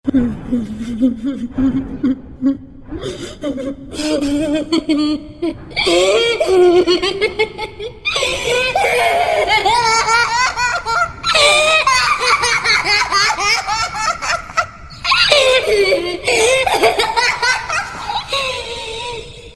Здесь собраны реалистичные аудиоэффекты: скрип половиц, завывание ветра в пустых комнатах, отдаленные голоса и другие жуткие детали.
Детский смех во тьме